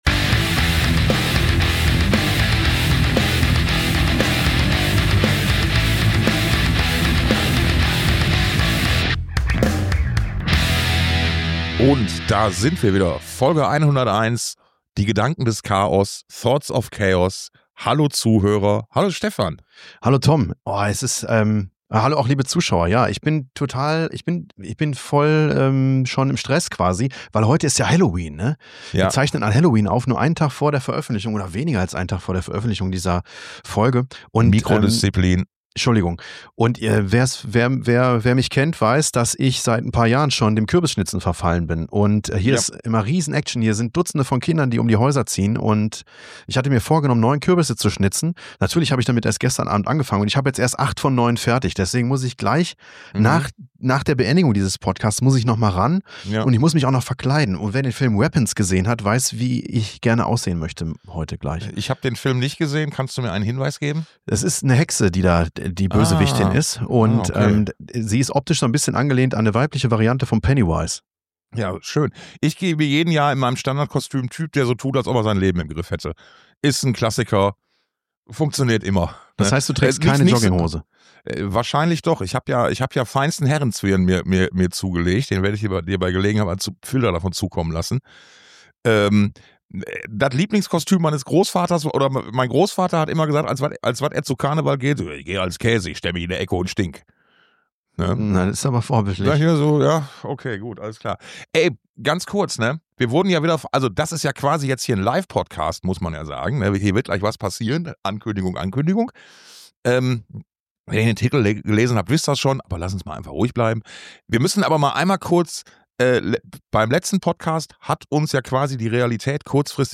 Und deswegen: Rudolf Schenker von den Scorpions bei Thoughts Of Chaos. Echt jetzt. 60 Jahre Rock’n’Roll und der Mann sprüht immer noch vor Energie wie einst im Mai.